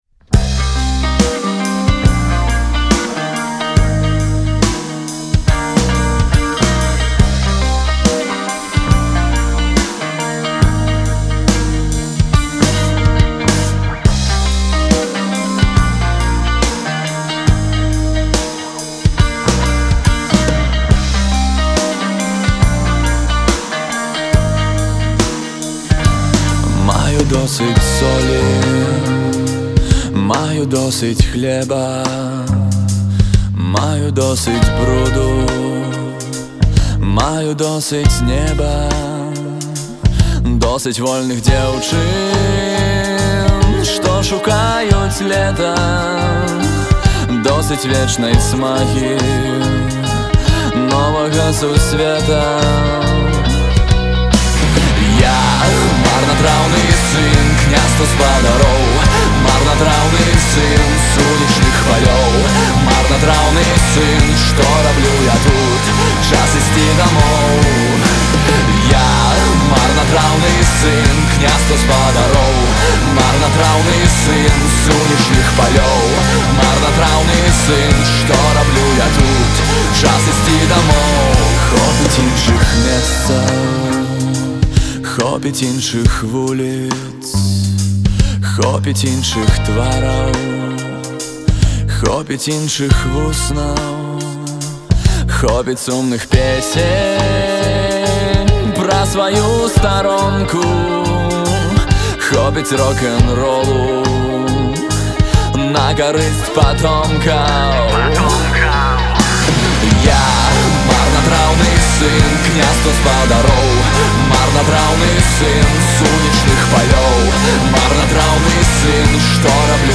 вакал, гітара
бас-гітара